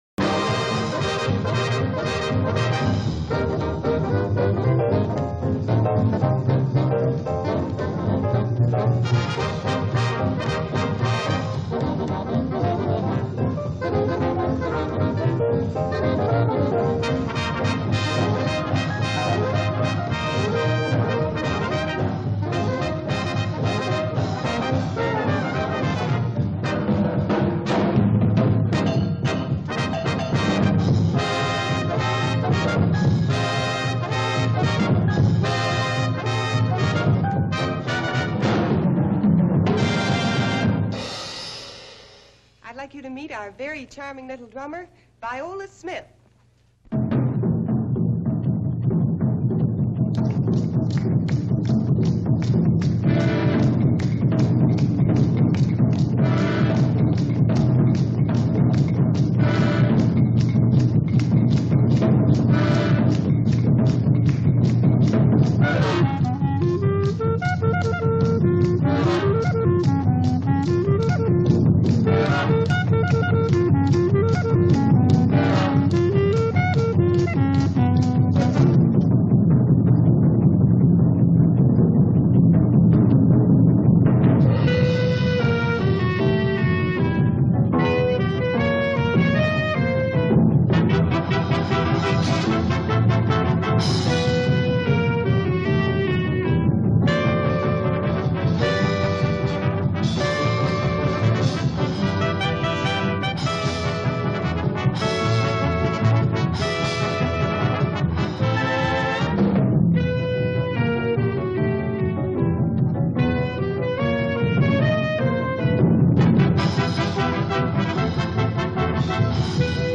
tanto en 1939, cuando tuvo lugar esta actuación